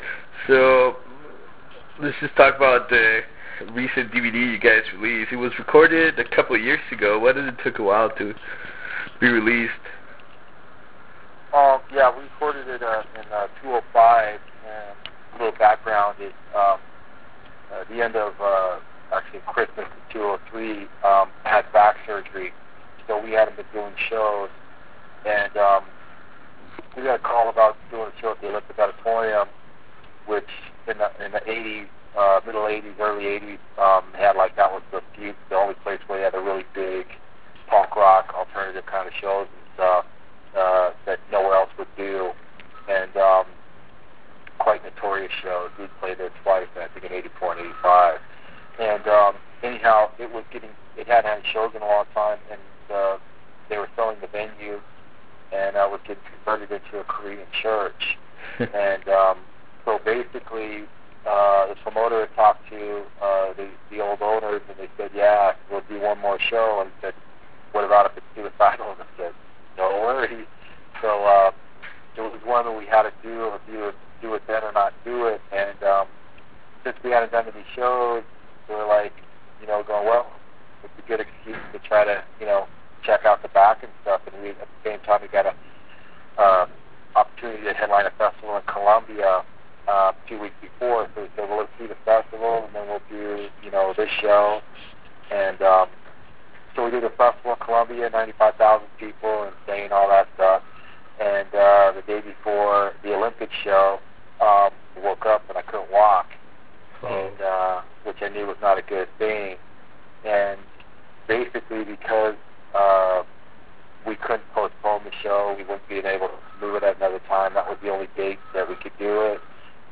Interview with Mike Muir from Suicidal Tendencies
Interview with Mike Muir - Suicidal Tendencies.wav